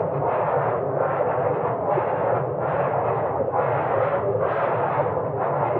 frostloop.wav